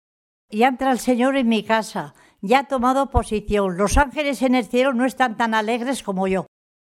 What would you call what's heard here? Clasificación: Oraciones